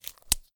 glove_put_on.mp3